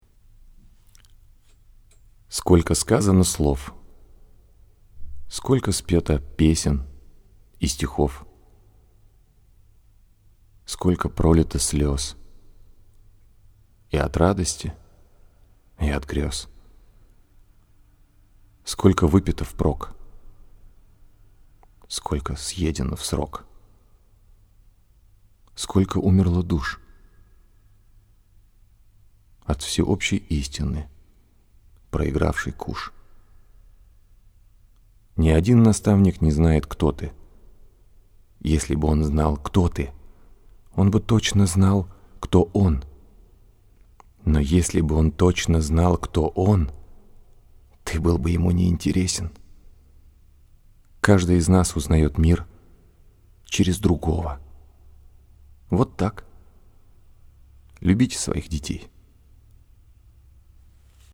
Пример голоса 2
Мужской
Баритон